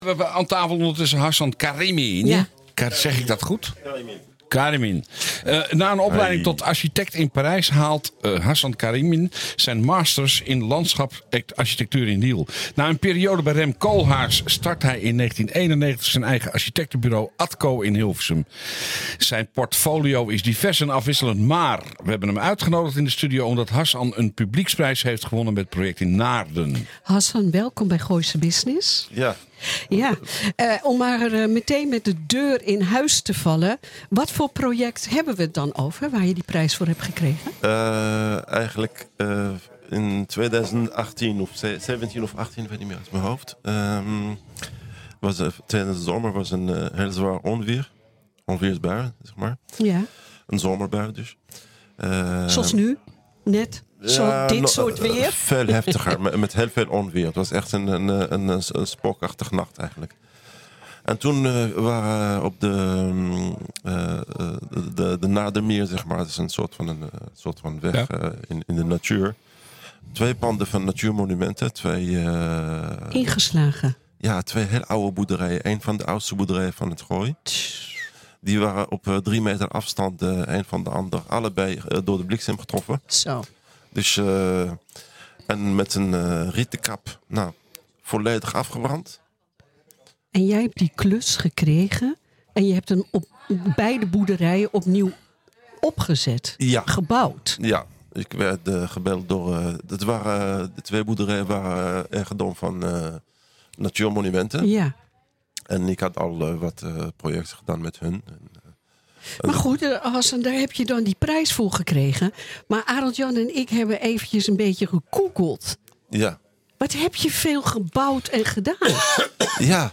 wij hebben hem uitgenodigd in de studio